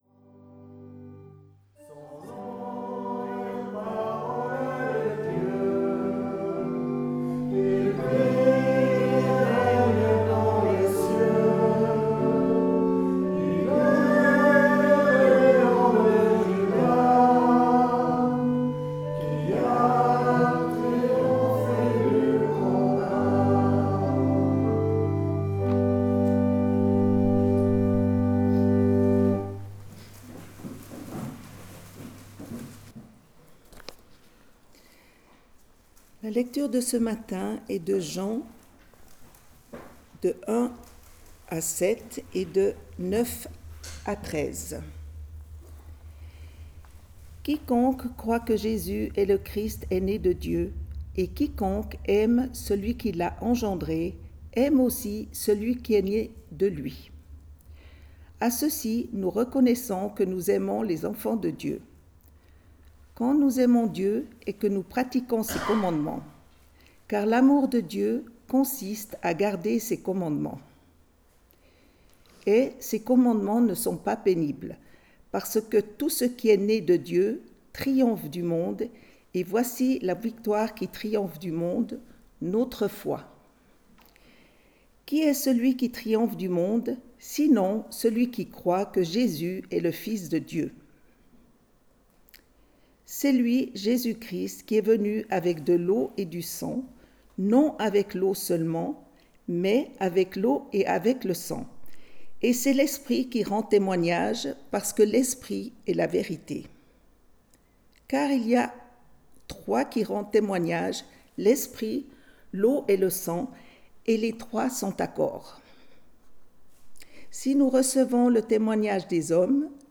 Type De Service: Classique